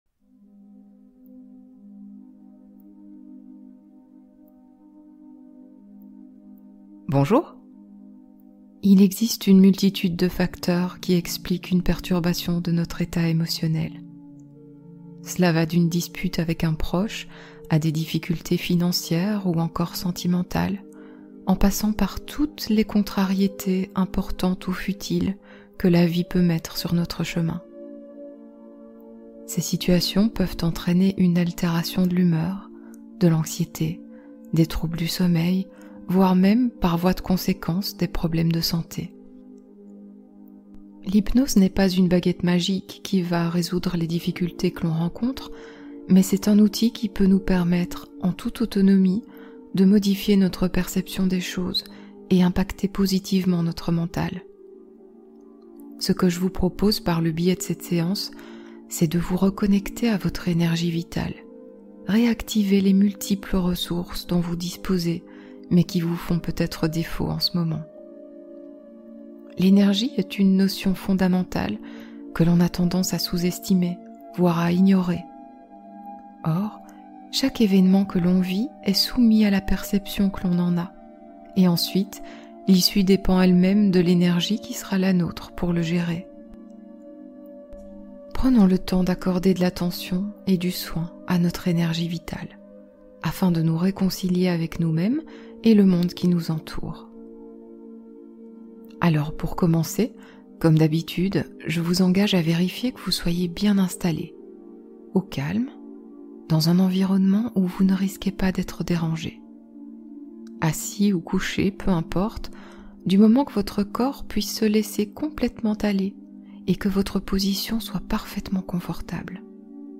Dépression et burn-out : hypnose d’accompagnement et de compréhension